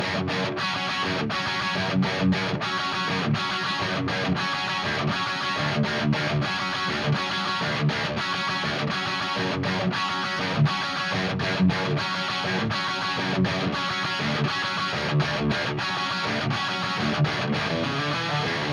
Вот пример, низ где-то от 300 слегка сужен, а верх звучит со стандартной шириной, за счёт этого получается некоторое движение, но лучше самому попробовать, это не что-то наглядное.